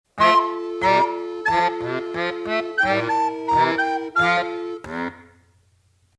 鈴木楽器製作所の鍵盤ハーモニカ「バスメロディオン B-24C」です。
手持ちの電源不要楽器（ソプラノ・アルト・テナーリコーダー、B-24C）で多重録音。童謡『ぶんぶんぶん』から冒頭２小節を。
音色は太く、多重録音で他の楽器と合わせた感じではこれで充分力強く支えてくれるような印象でした。